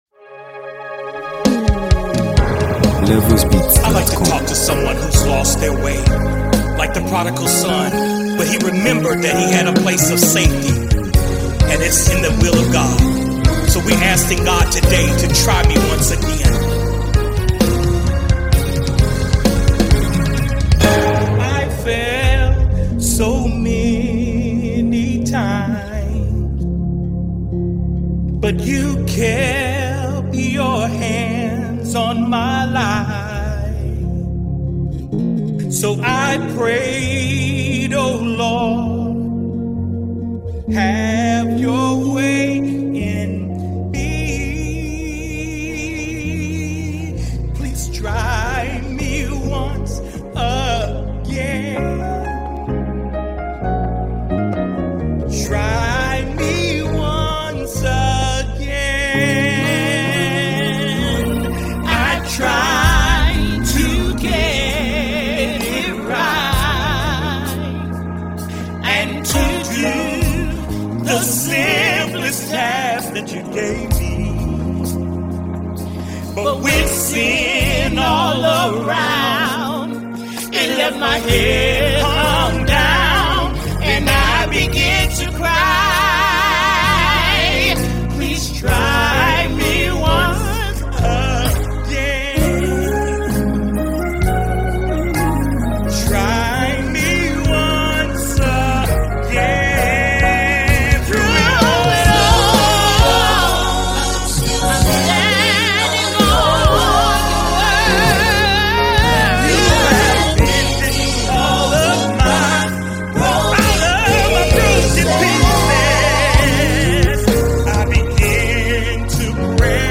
worship song
if you appreciate gospel music that inspires repentance